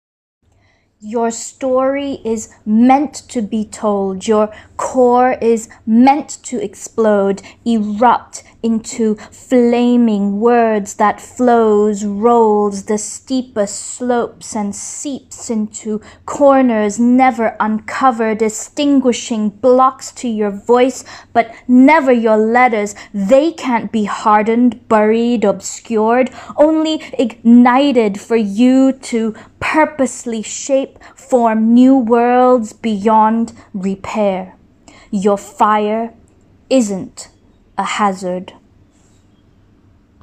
Poets